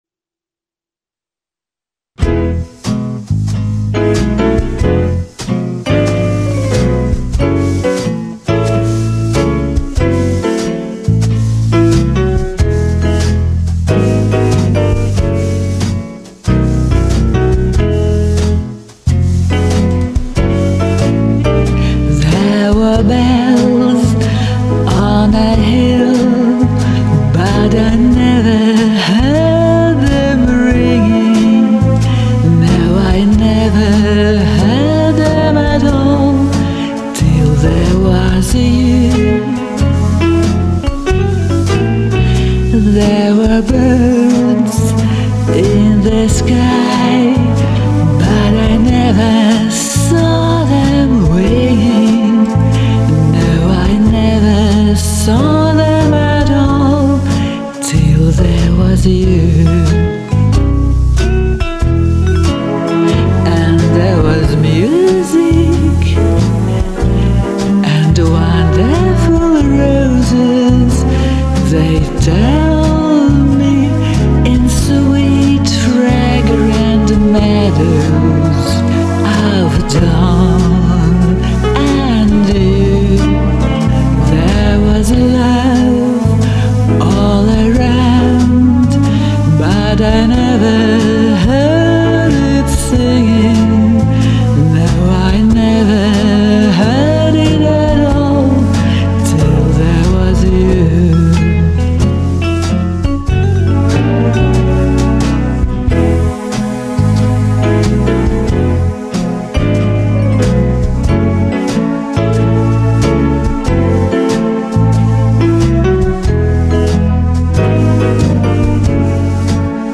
с джазовым привкусом для меня прозвучала приятнее